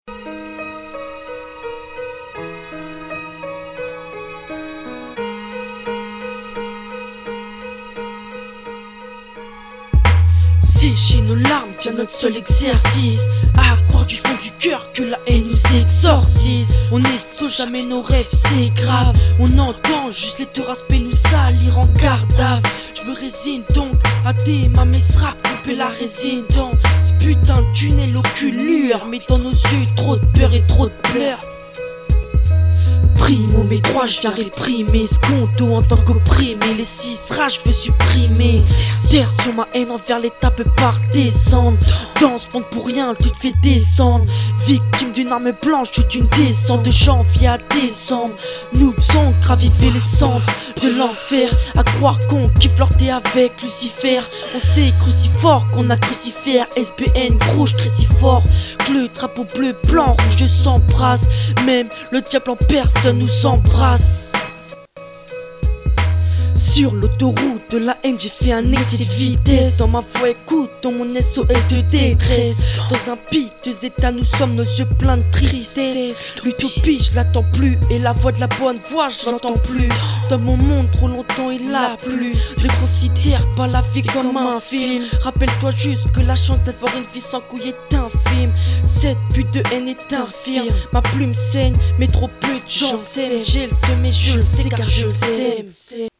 Catégorie : Rap -> Music